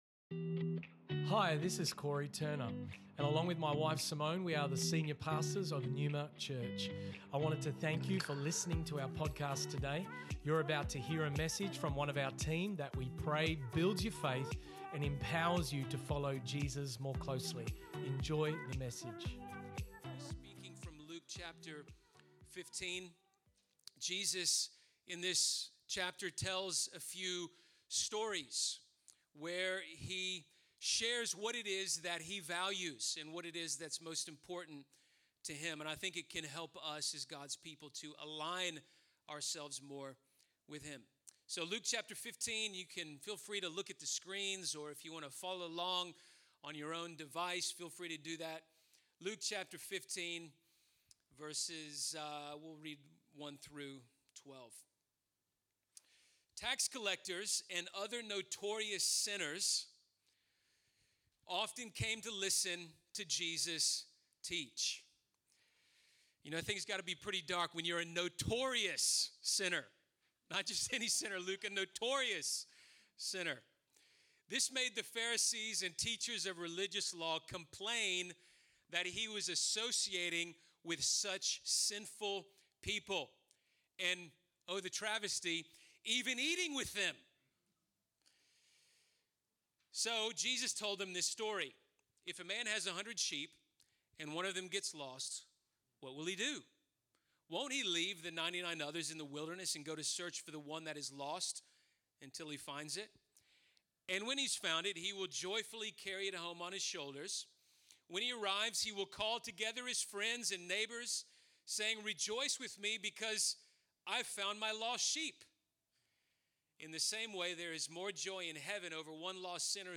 Luke 15:1-12 This message was originally preached at NEUMA Church Perth on Sunday, 19 September 2021.